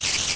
minecraft / sounds / mob / spider / say4.ogg